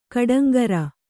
♪ kaḍaŋgara